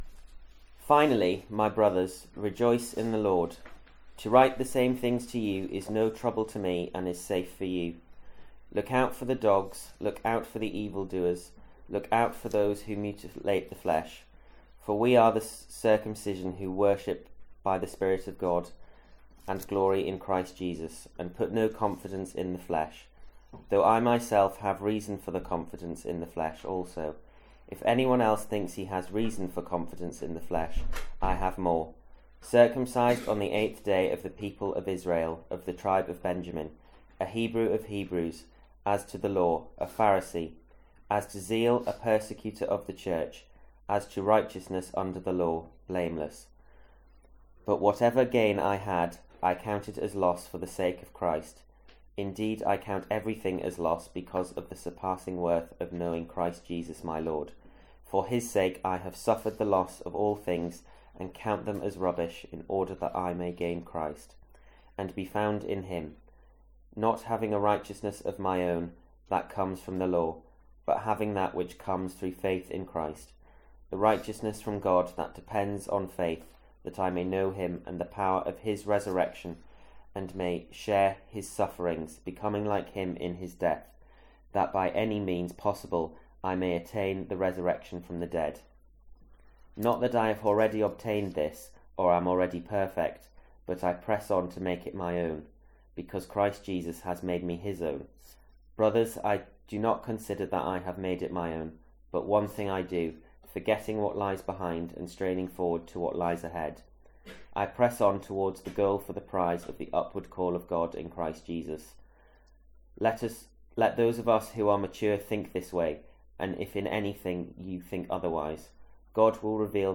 Sermons | St Andrews Free Church
Talk 3 from MYC15.